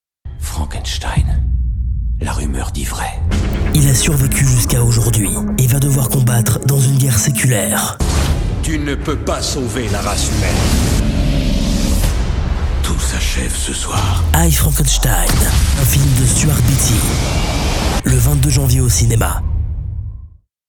FRENCH VO ENGLISH SPOKEN
Sprechprobe: Sonstiges (Muttersprache):